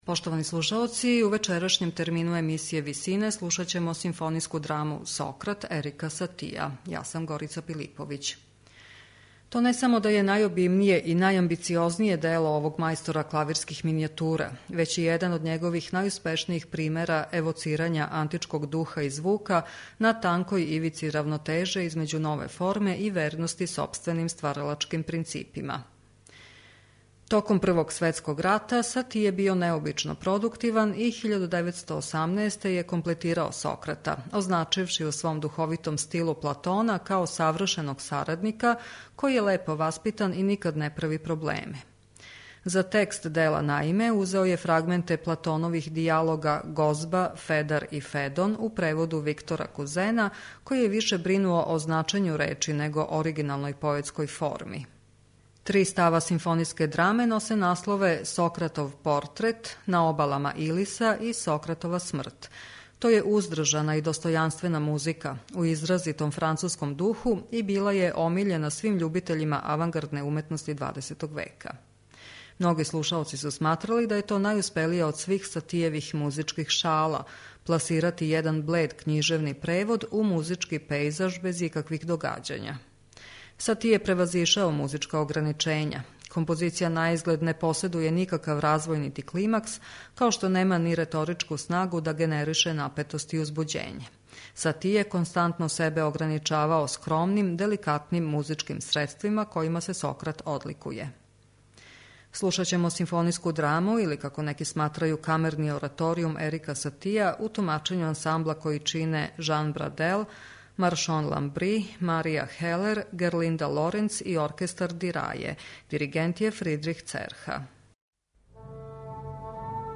симфонијску драму у три дела за глас и оркестар